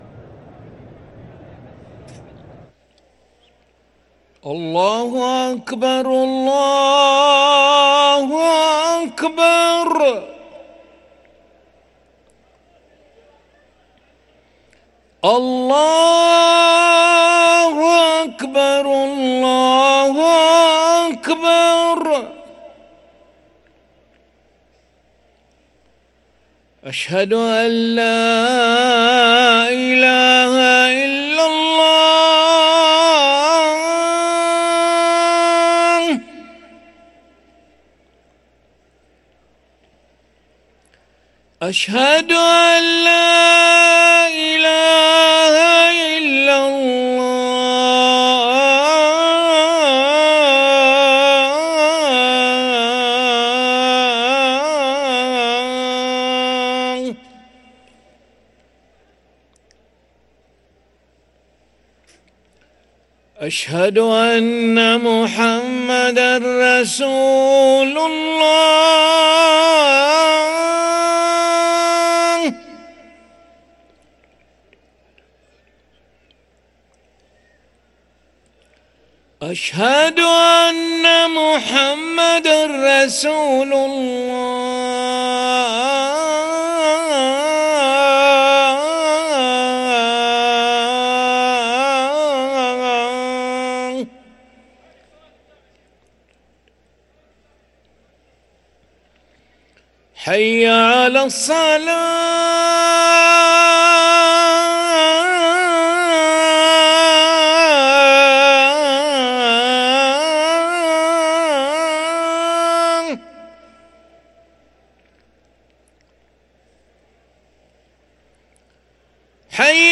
أذان العشاء للمؤذن علي بن أحمد ملا الخميس 7 شوال 1444هـ > ١٤٤٤ 🕋 > ركن الأذان 🕋 > المزيد - تلاوات الحرمين